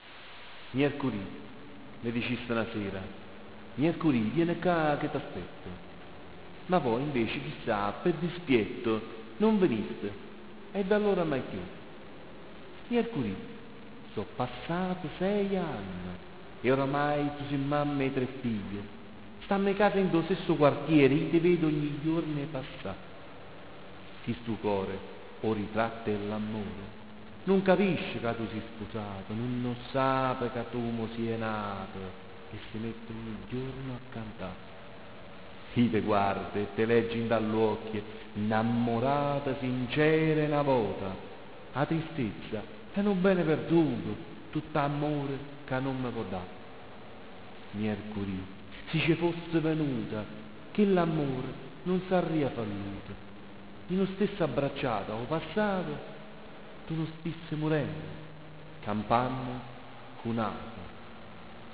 La poesia sonora si collega per un verso alla musica e per un altro al teatro, da un lato abbina il testo poetico ad un brano musicale, dall'altro sfrutta la sonorità del linguaggio.